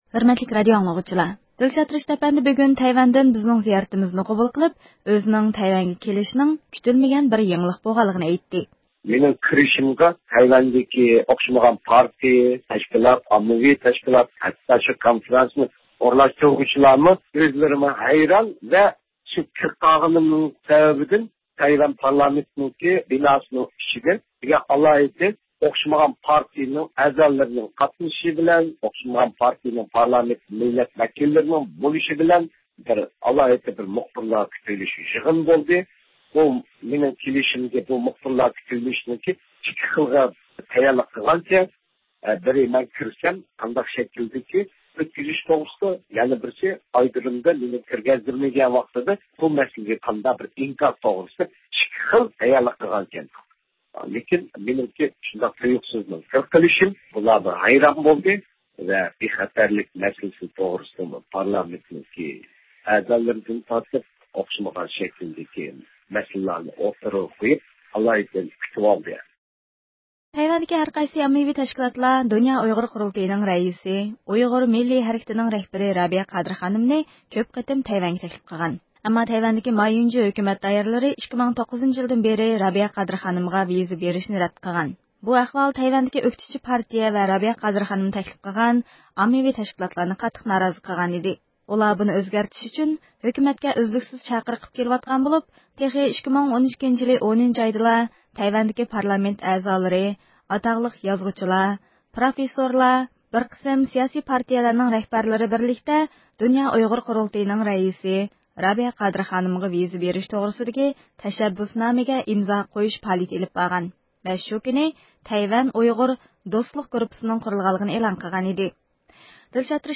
ھۆرمەتلىك رادىئو ئاڭلىغۇچىلار